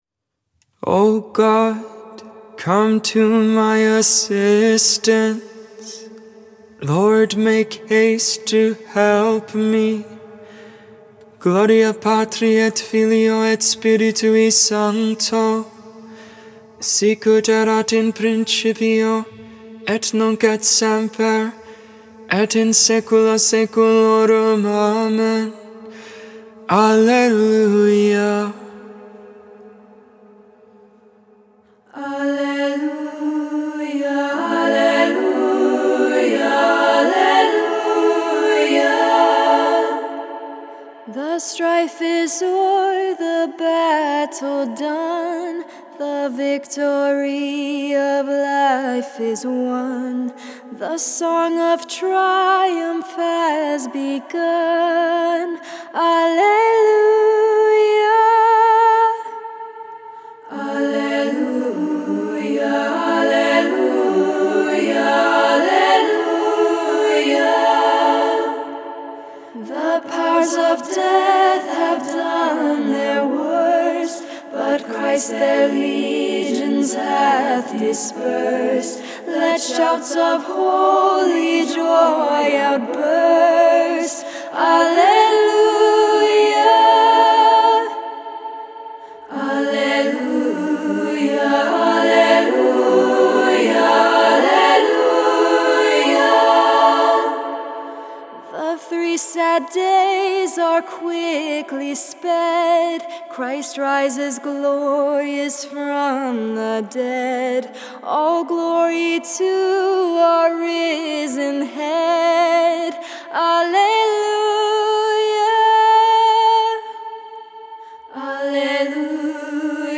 English, tone 8